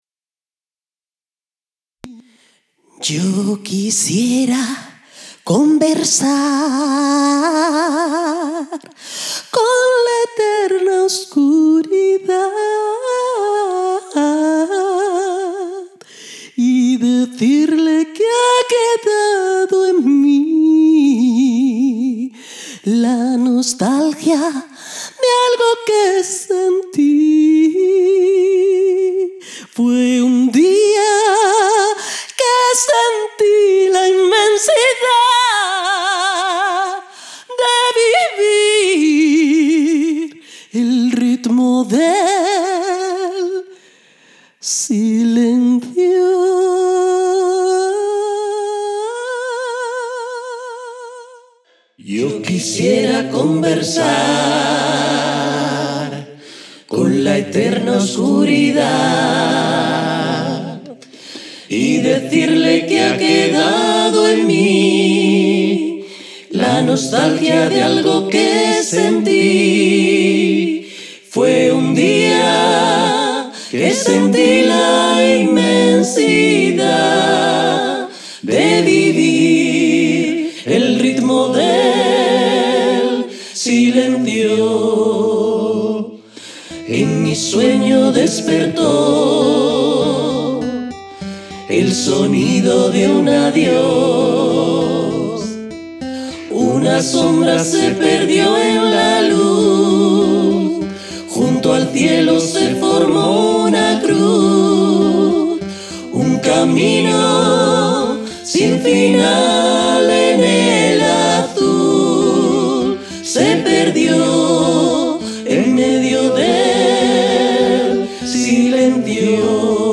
interpretados a capella